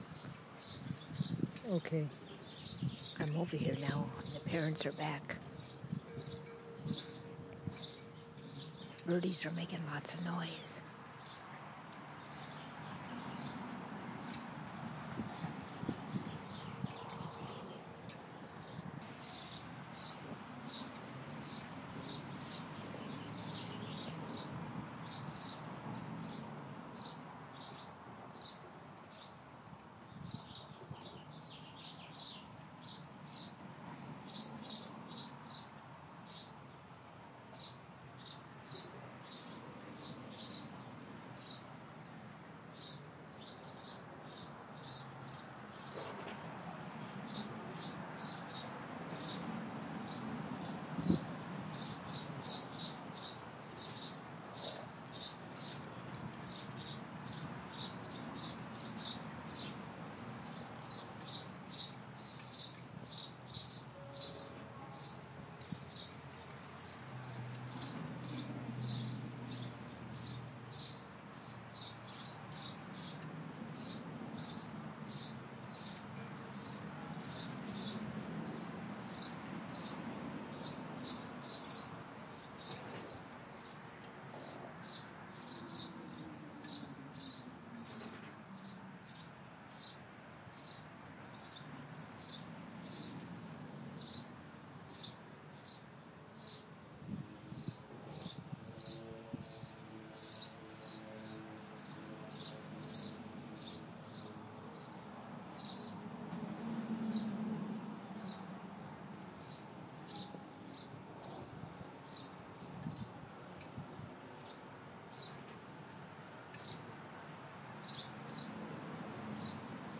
Just Birdies Chirping in Town